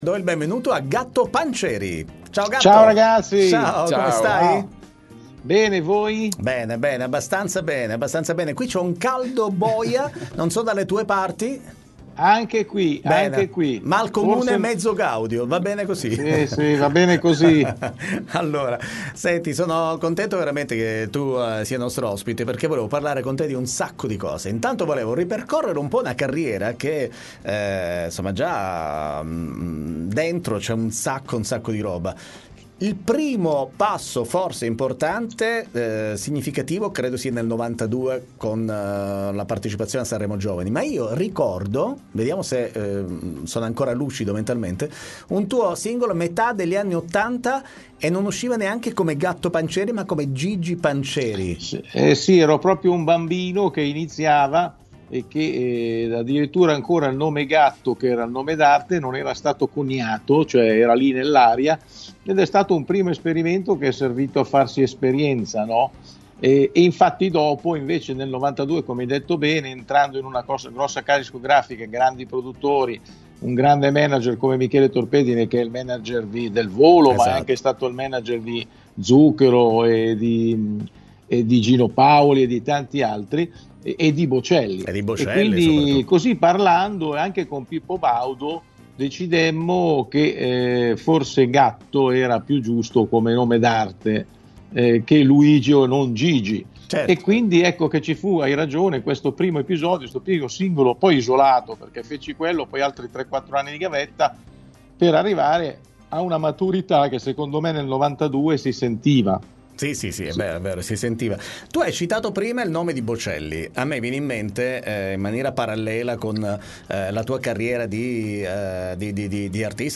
intervista Gatto Panceri